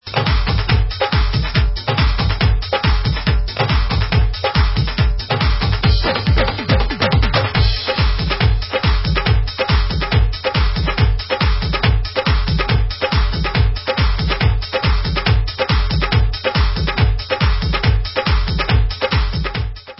Rhythm Pattern 5